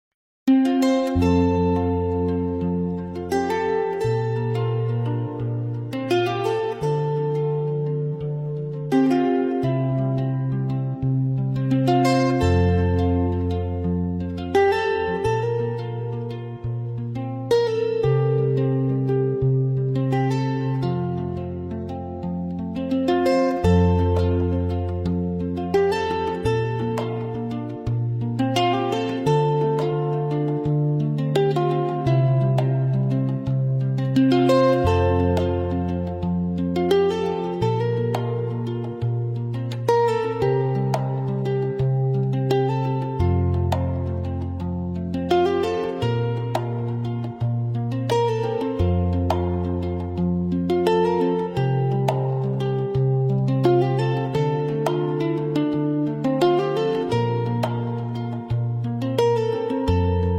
Kora-Instrumental-Heart-of-West-African-Music.mp3